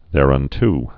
(thârŭn-t)